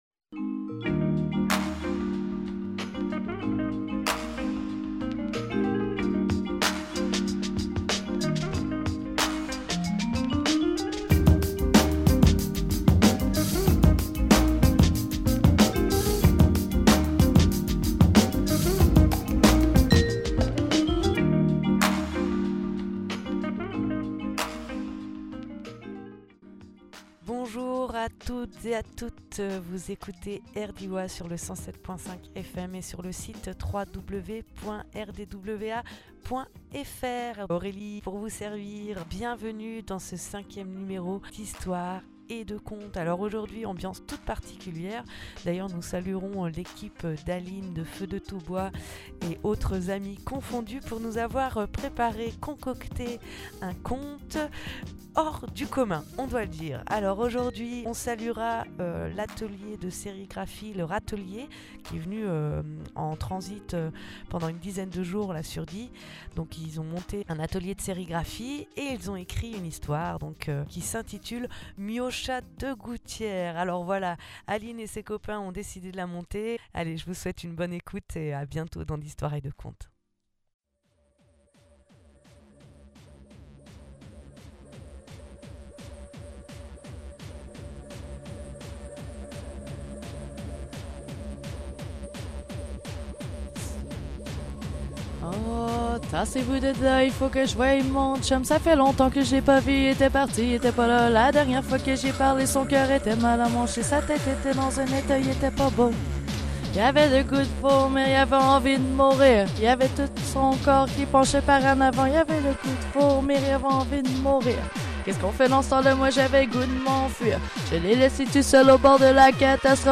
Lieu : Studio Rdwa